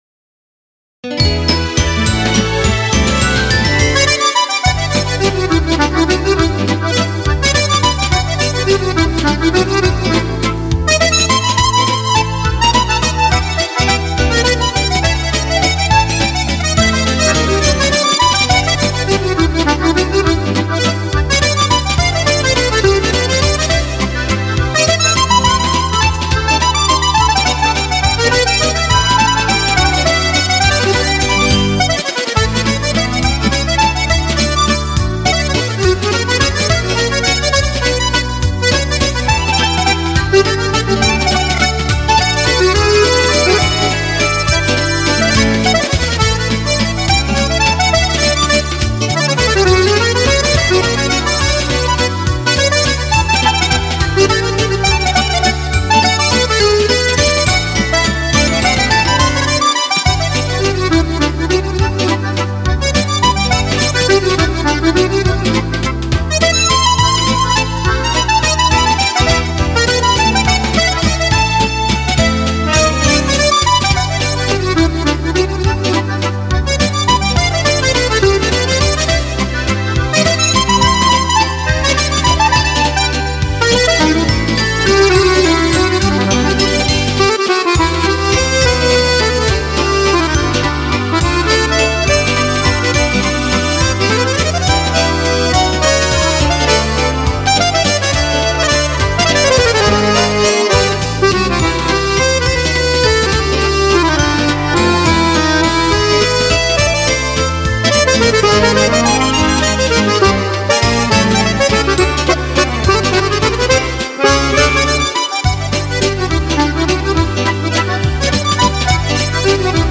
键钮式手风琴演奏